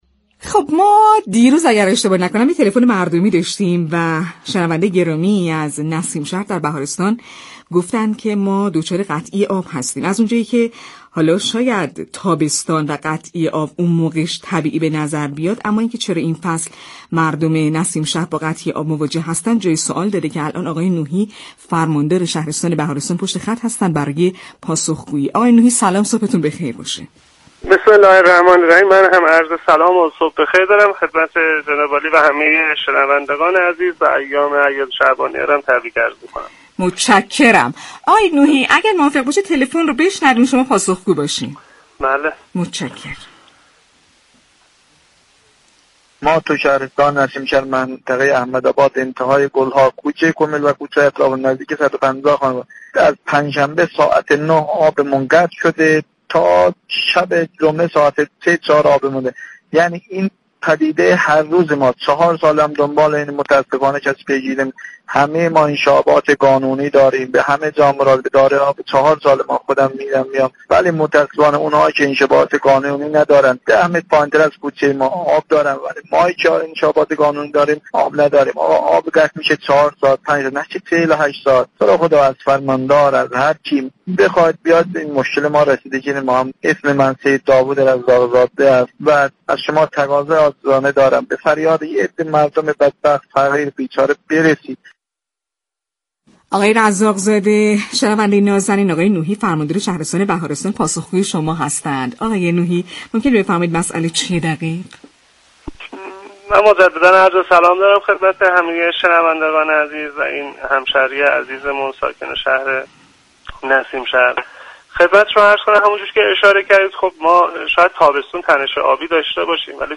در برنامه روز 14 اسفند یكی از شنوندگان برنامه از نسیم شهر بهارستان منطقه احمدآباد- انتهای گلها عنوان كرد علیرغم اینكه دارای انشعاب قانونی با قطعی آب مكرر مواجه هستند. در همین راستا برنامه «شهر آفتاب» در گفت و گو با فرماندار بهارستان این مشكل را جویا شد.